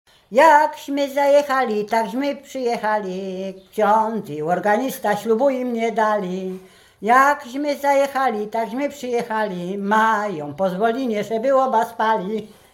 Łęczyckie
Weselna
weselne na wyjazd z kościoła przyśpiewki